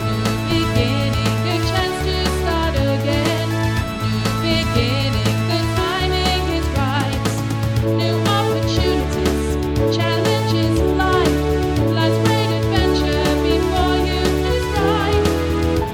Music Samples